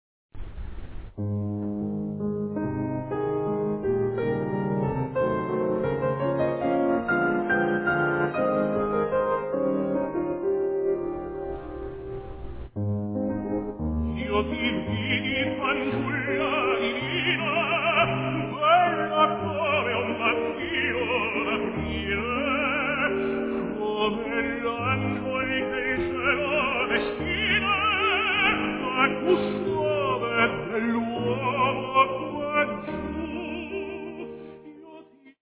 Canzone
accompagné au piano